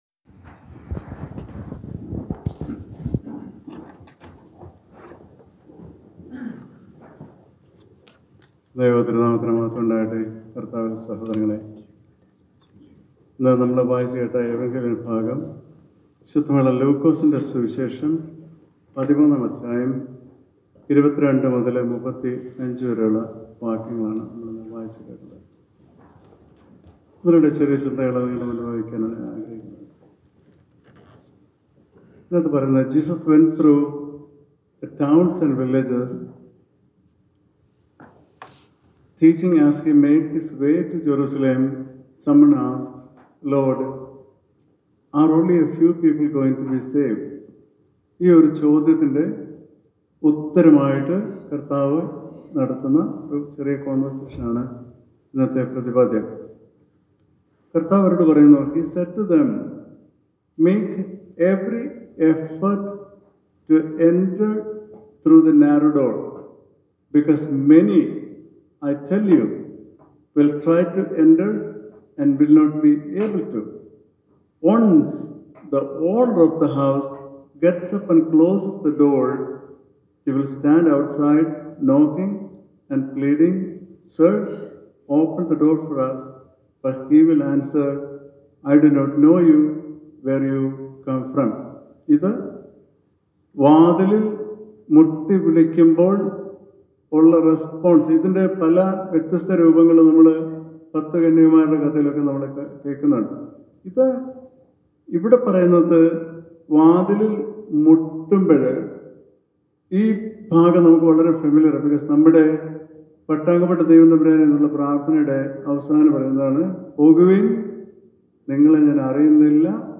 Sermon – July 20 -2025 – St. Luke 13: 22-35